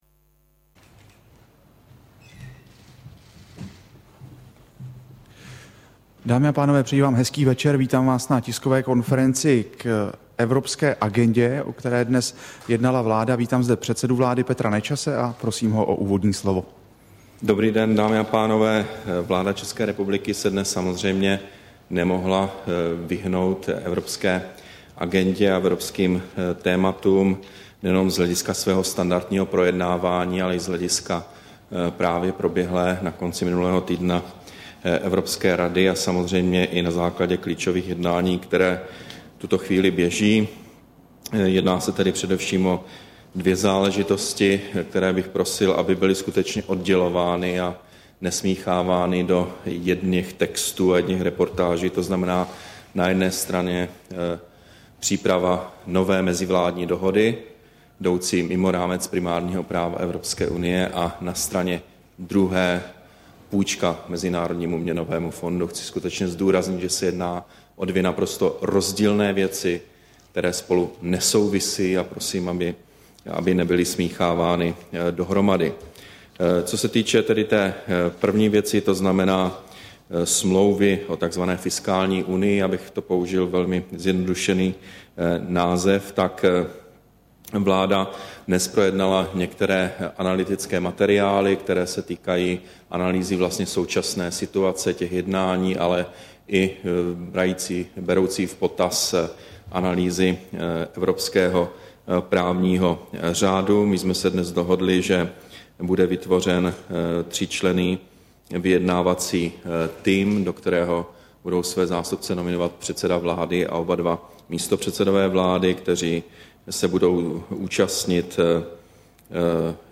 Tisková konference po projednání prvních dvou bodů jednání vlády týkajících se Evropské unie, 14. prosince 2011